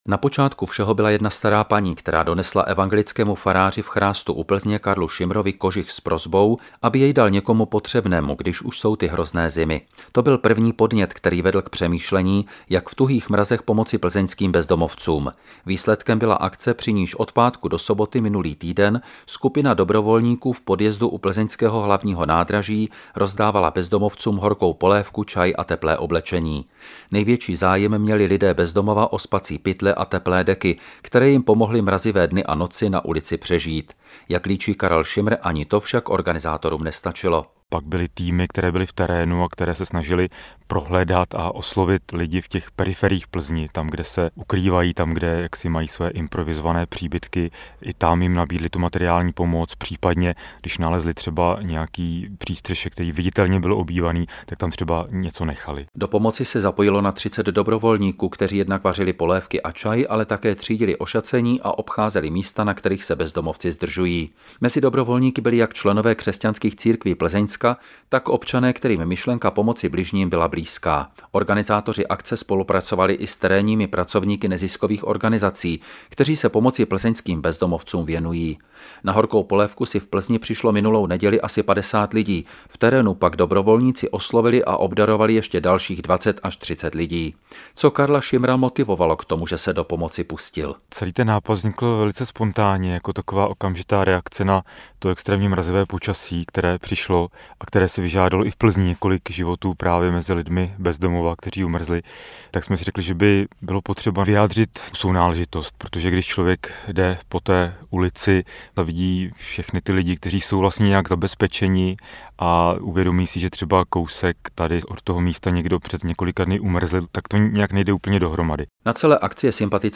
zprávu